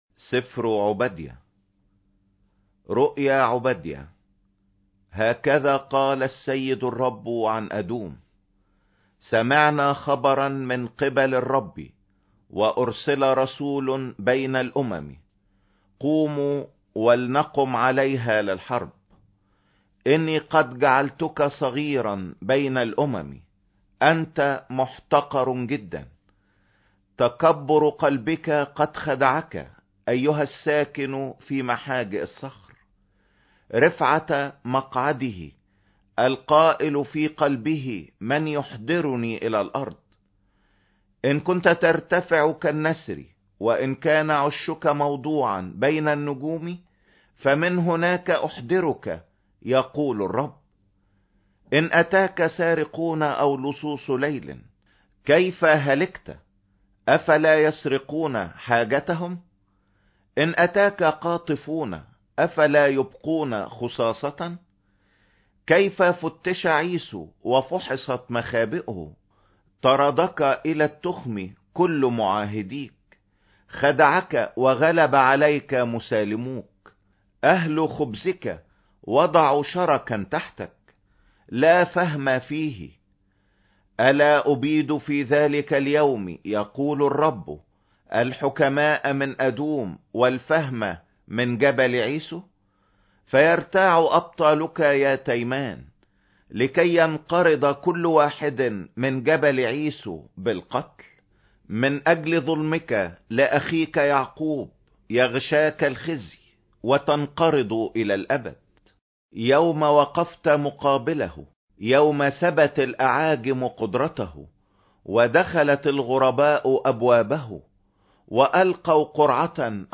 سفر عوبديا - قناة المجد - الانجيل مسموع ومقروء